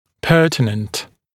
[‘pɜːtɪnənt][‘пё:тинэнт]уместный, имеющий отношение, относящийся к делу, подходящий